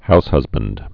(houshŭzbənd)